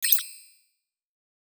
Game Tutorial Notification Sound.wav